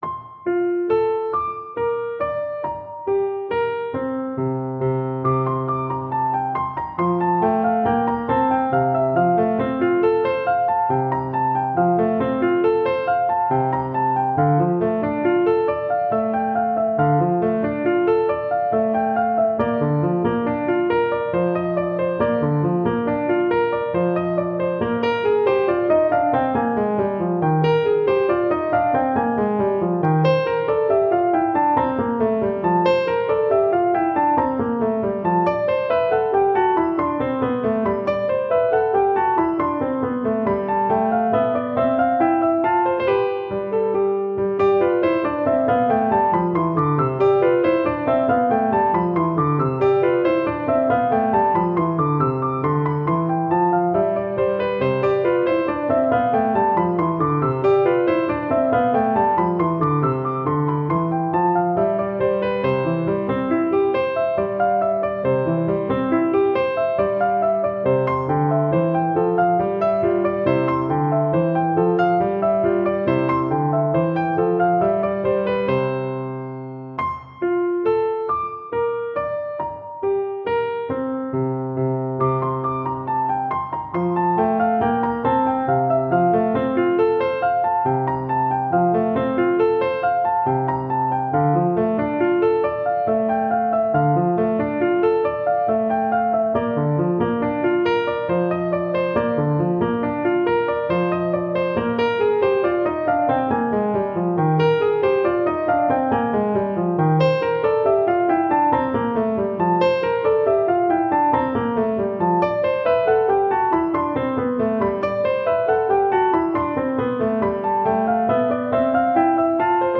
• Duet (Violin / Viola)